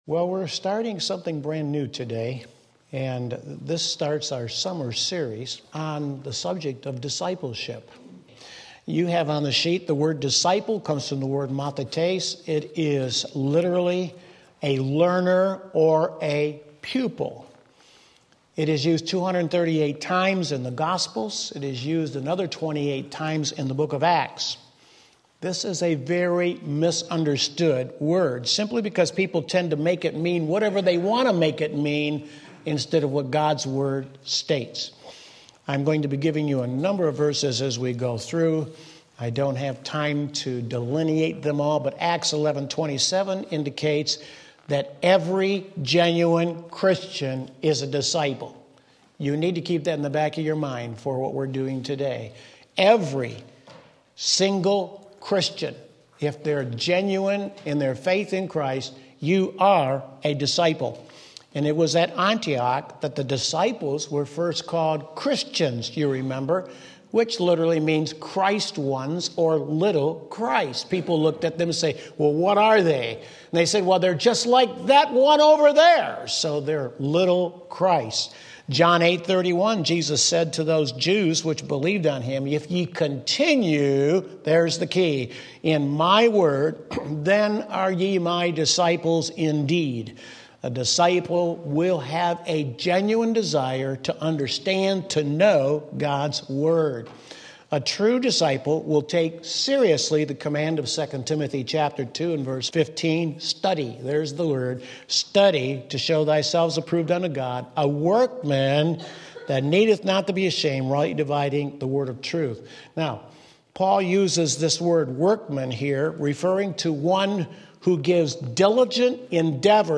Sermon Link
2 Timothy 2:2 Sunday School